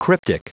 Prononciation du mot cryptic en anglais (fichier audio)
Prononciation du mot : cryptic